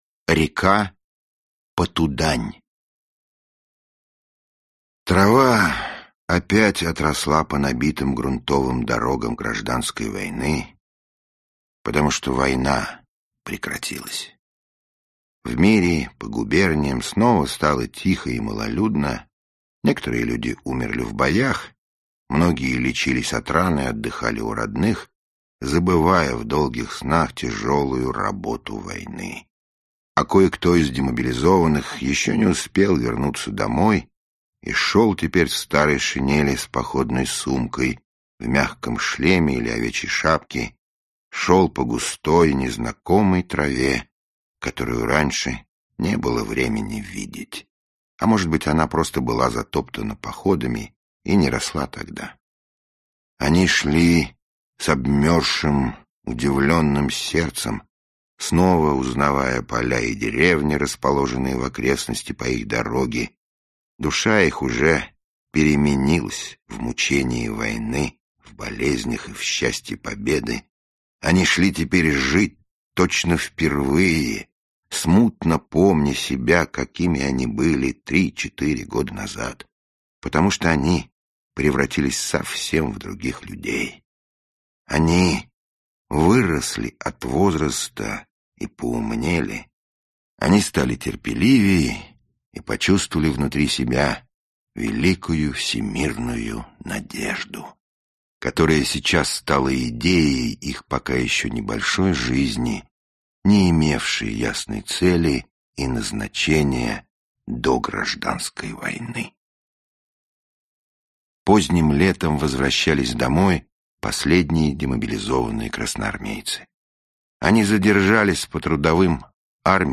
Аудиокнига Река Потудань | Библиотека аудиокниг
Aудиокнига Река Потудань Автор Андрей Платонов Читает аудиокнигу Михаил Горевой.